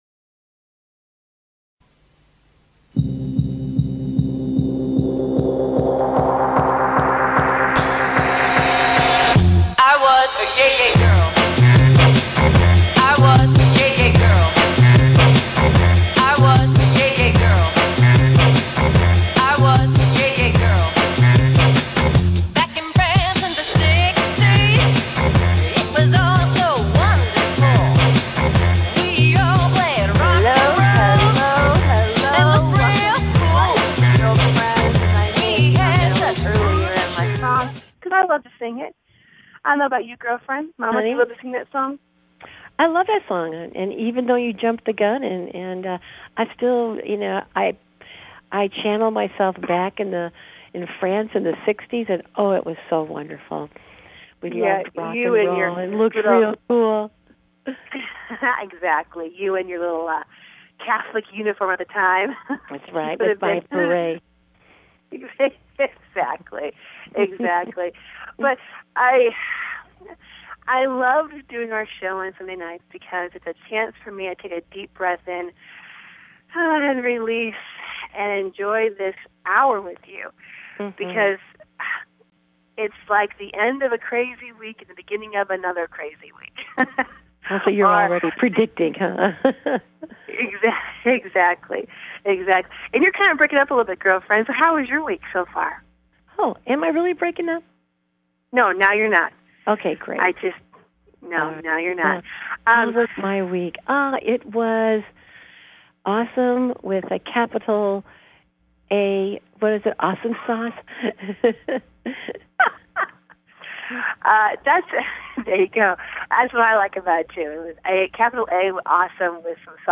Talk Show Episode
Interview with two guests